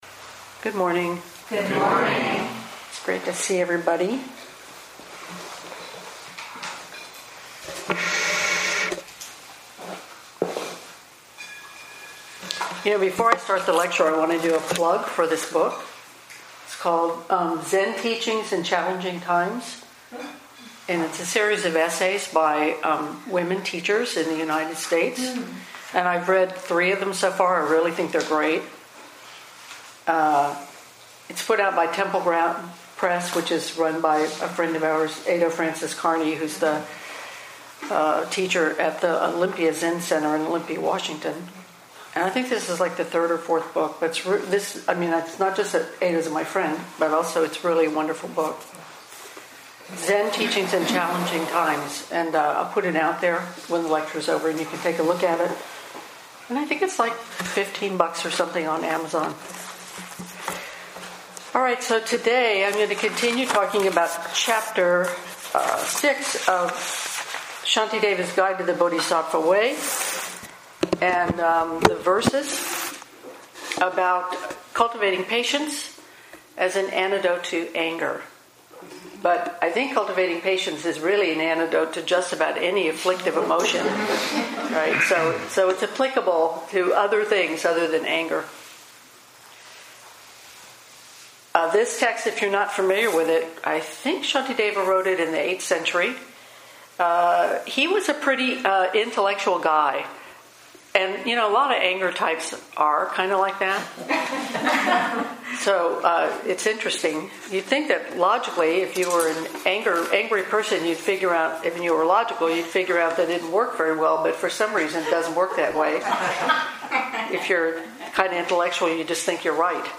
Shantideva-ch-6-lecture-5-compressed.mp3